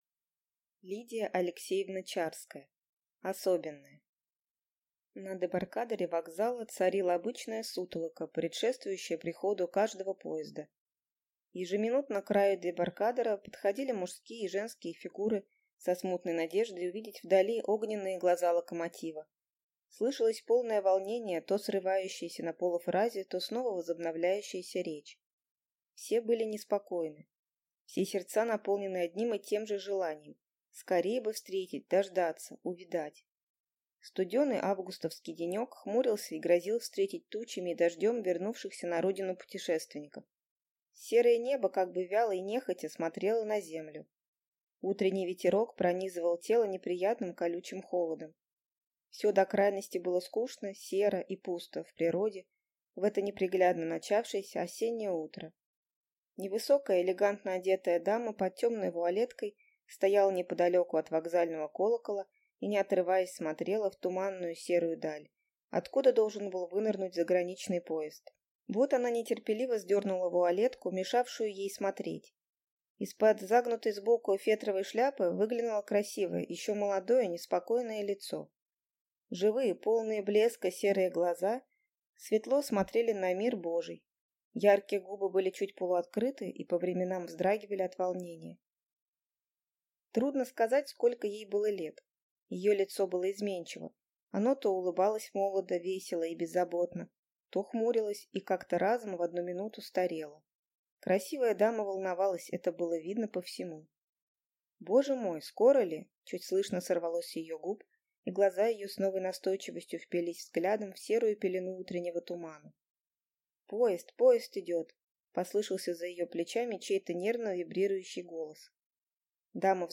Аудиокнига Особенная | Библиотека аудиокниг